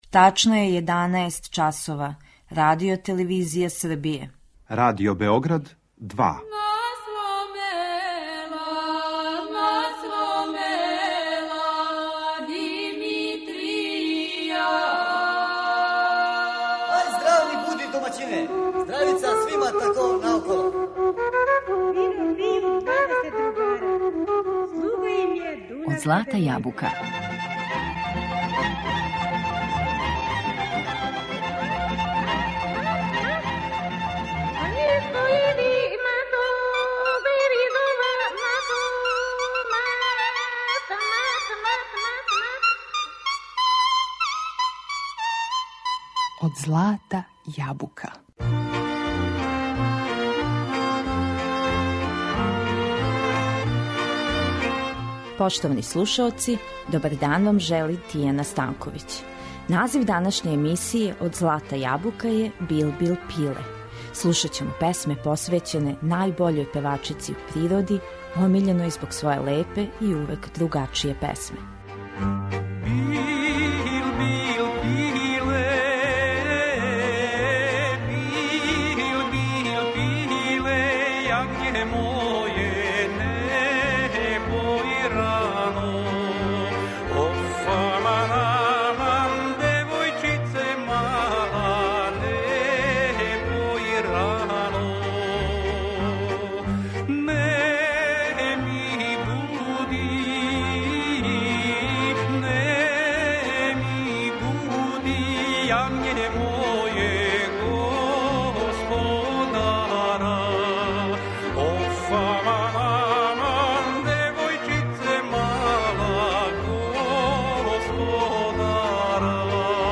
Билбил или булбул је реч која је у наш језик стигла из турског језика и одомаћила се у песмама, претежно са југа, које ћемо слушати у данашњем издању емисије.